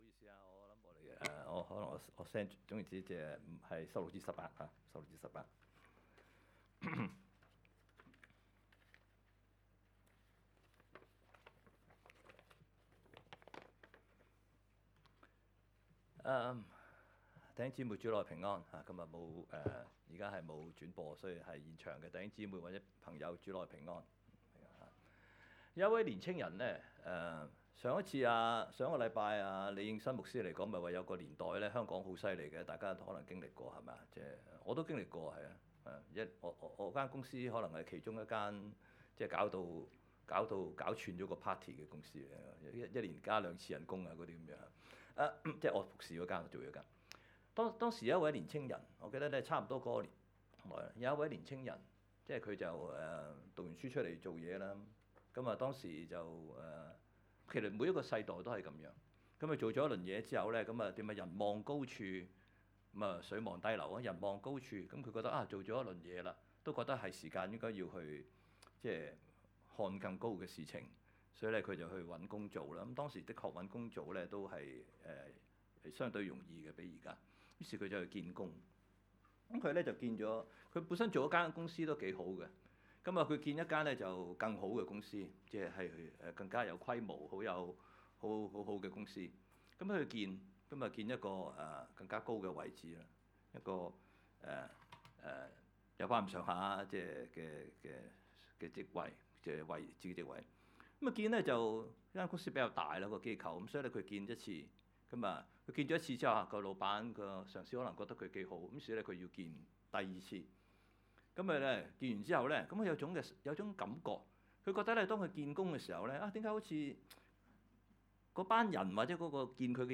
講道 ：更上層樓